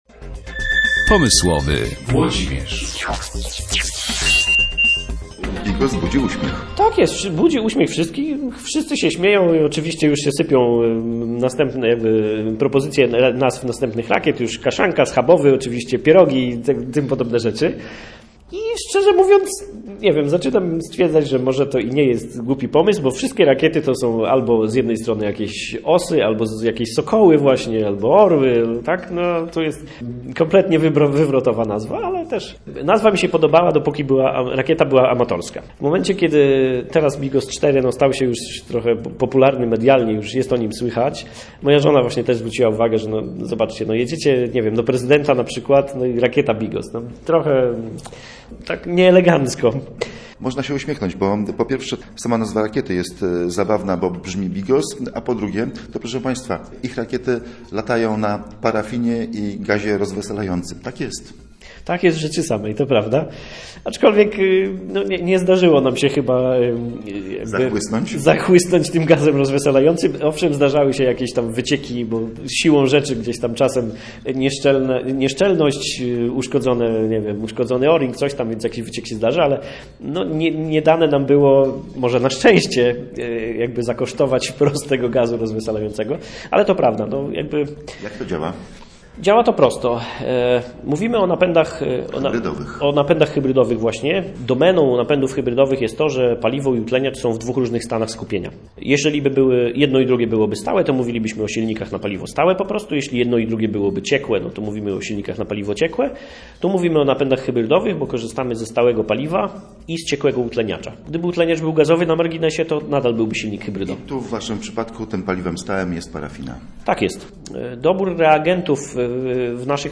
Radio Gdańsk W Centrum Nauki EXPERYMENT odbyło się kolejne spotkane w ramach cyklu dla dorosłych Science Cafe.